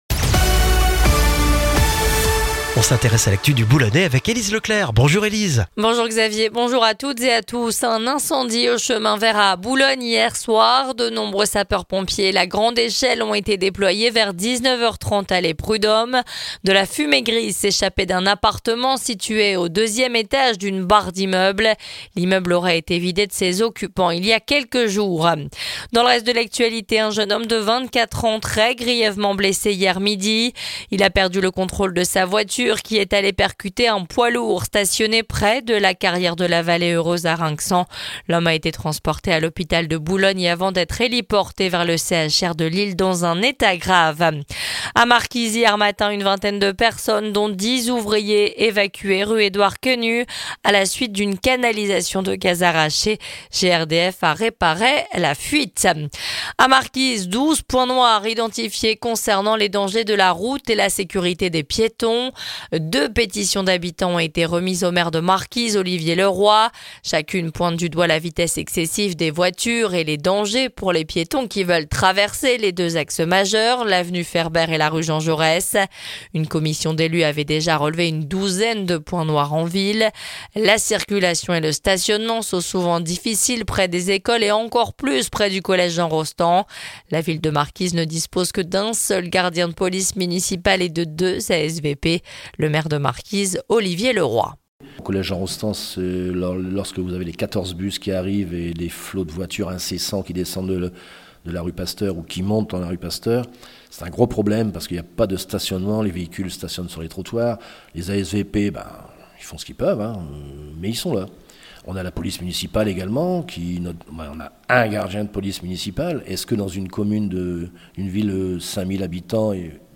Le journal du mercredi 2 octobre dans le Boulonnais